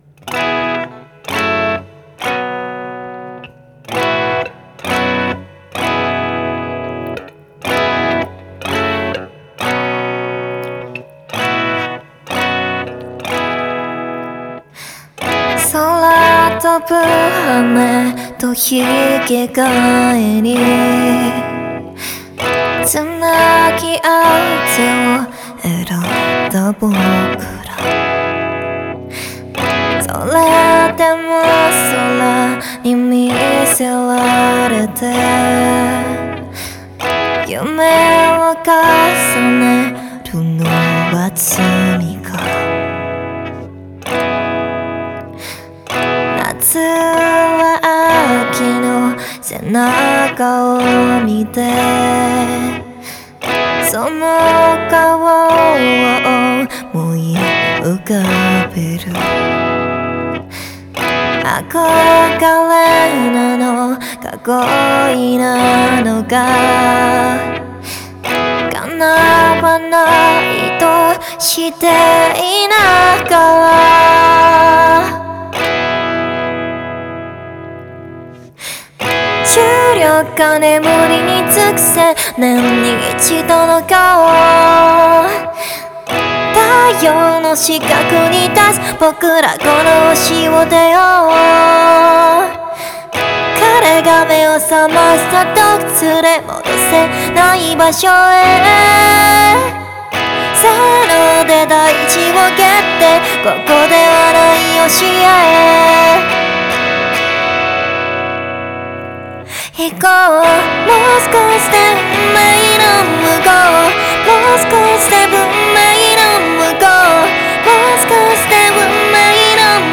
[00:36] 최근에 연습중인건대..
[00:40] 아마..깔끔하게 들릴거예요ㅕ 푸히힣\
[00:40] ㅋㅋㅋㅋㅋㅋㅋㅋㅋ방금 후다닥 치고 불렀어요 ㅠ